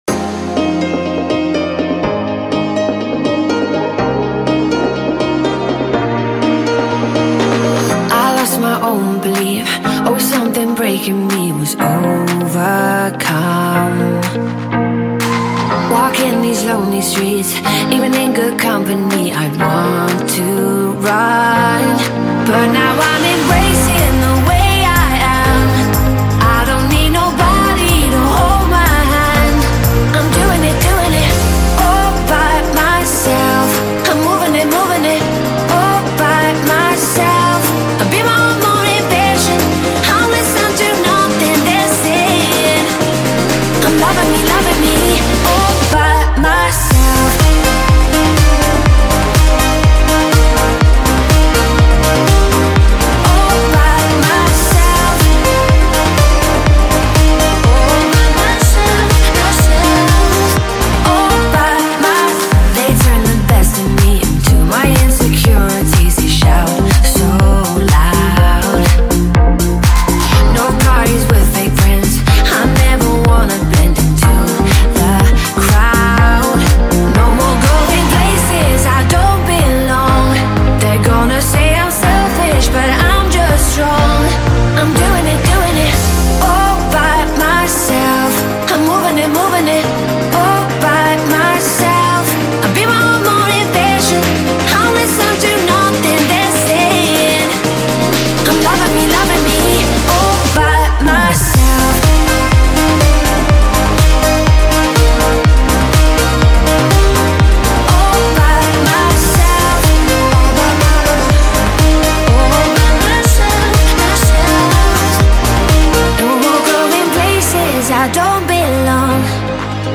Kategorie POP